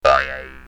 bounce3.ogg